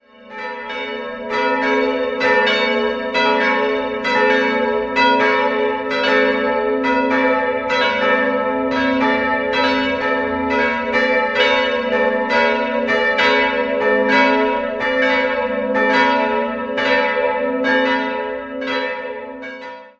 Jahrhundert (Deckengemälde). 3-stimmiges Paternoster-Geläute: a'-h'-cis'' Die drei Glocken wurden im Jahr 1950 von Karl Czudnochowsky in Erding gegossen.